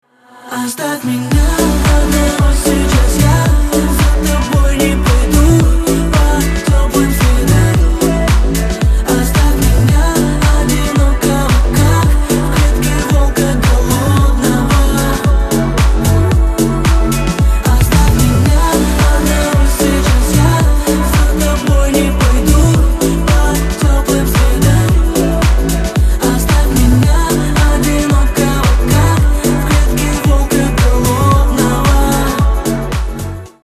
• Качество: 128, Stereo
поп
мужской вокал
грустные
dance